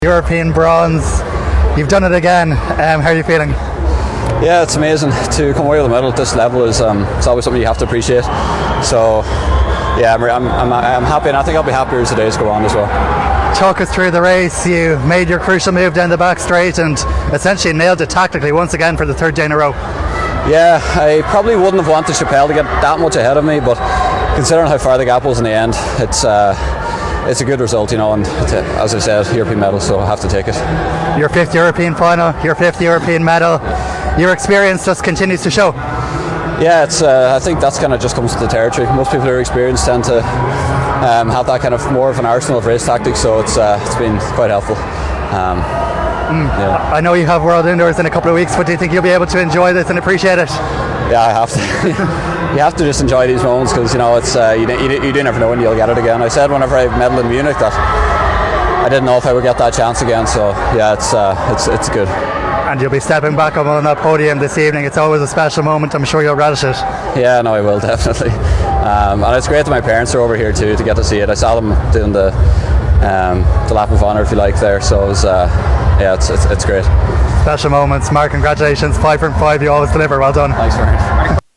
Afterwards English gave his reaction to Athletics Ireland: